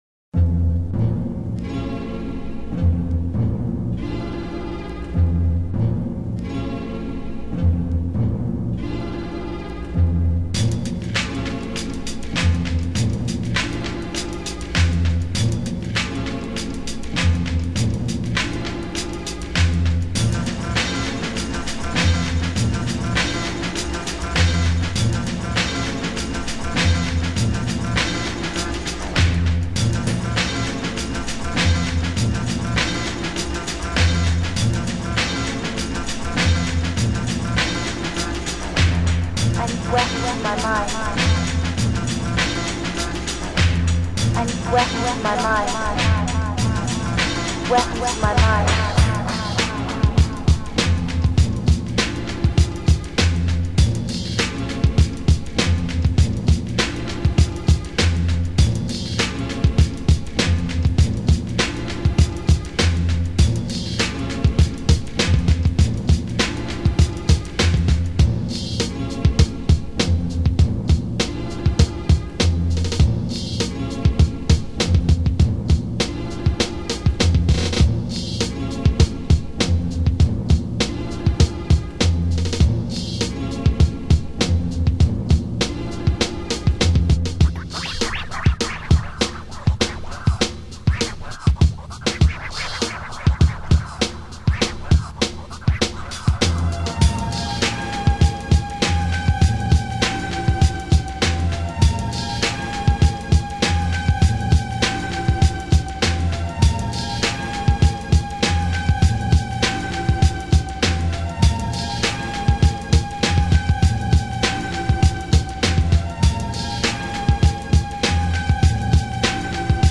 pumped up...blissed out electronic beats
fusing the best elements of big beats and trip hop